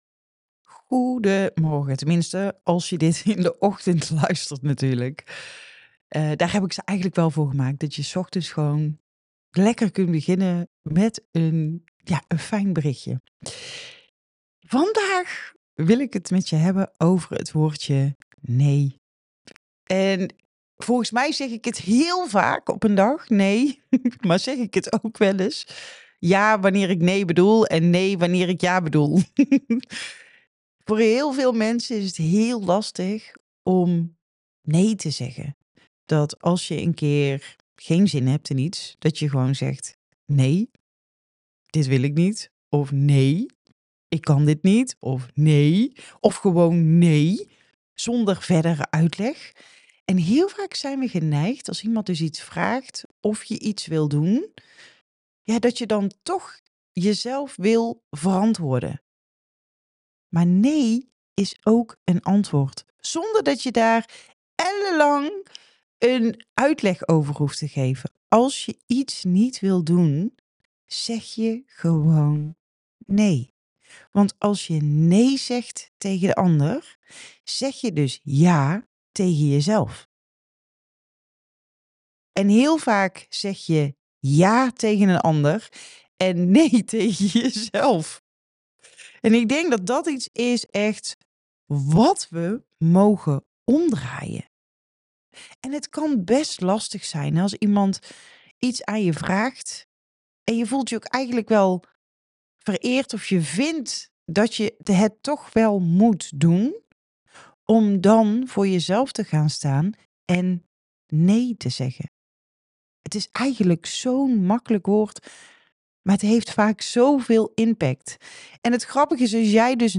Herken jij dat je vaak ‘ja’ zegt uit beleefdheid, terwijl je eigenlijk ‘nee’ bedoelt? In het voicebericht van vandaag neem ik je mee in het belang van nee zeggen en hoe dit een ‘ja’ kan zijn voor jezelf.